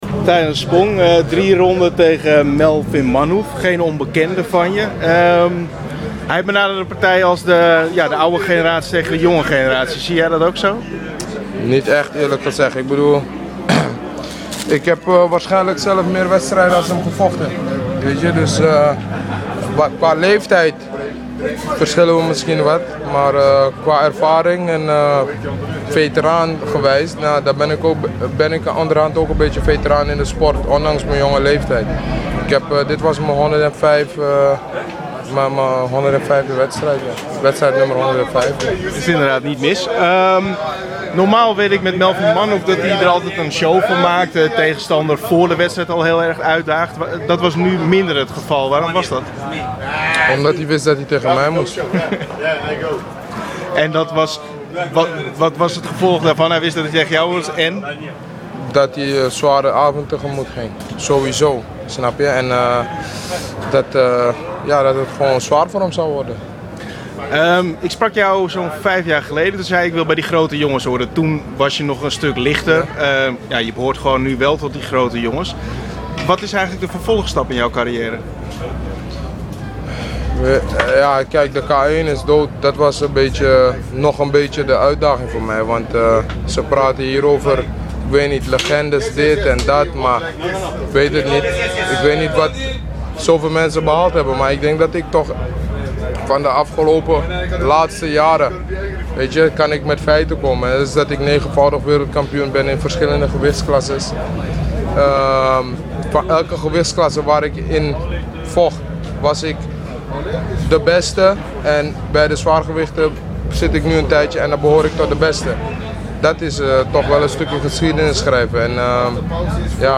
Interview Tyrone Spong 2012 januari gewonnen tegen Melvin Manhoef